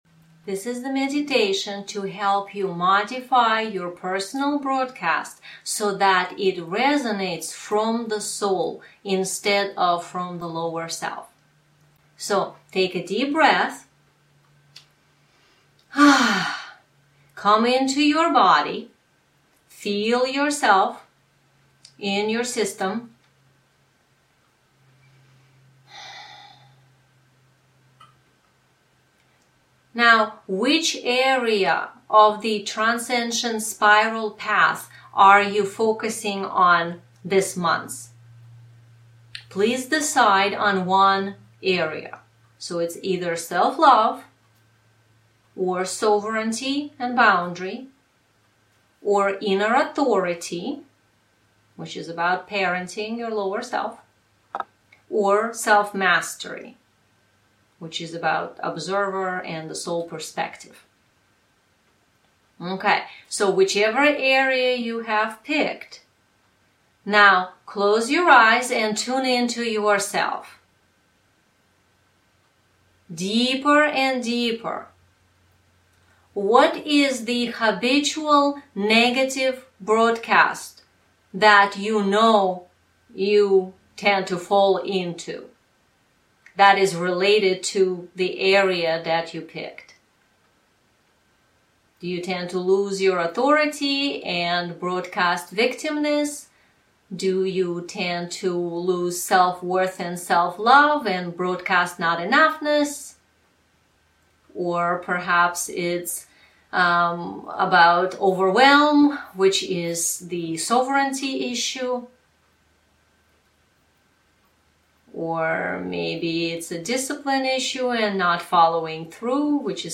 TGM_Broadcast_Meditation.mp3